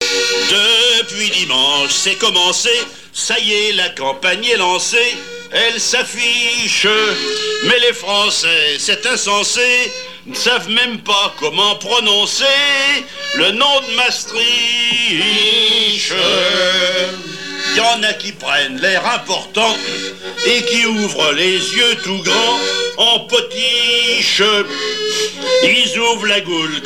Patois local
Genre strophique
Pièce musicale inédite